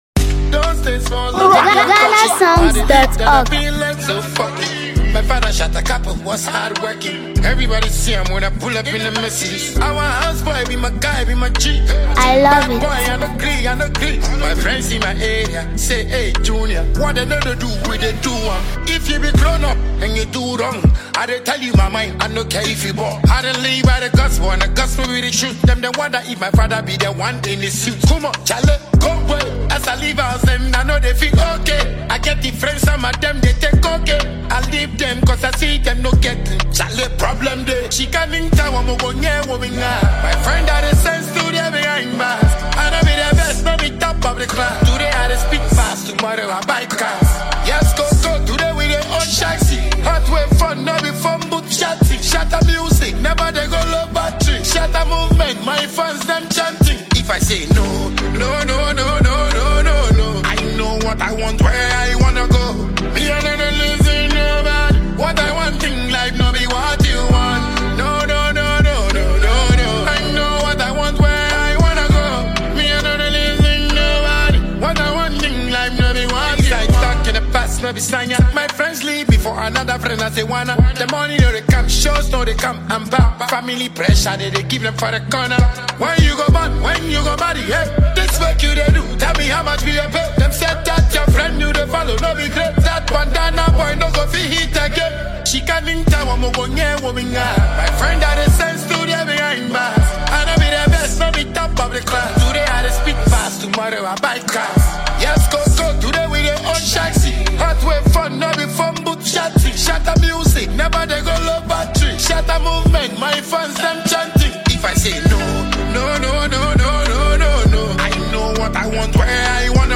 Known for his energetic delivery and consistent hit releases
dynamic vocals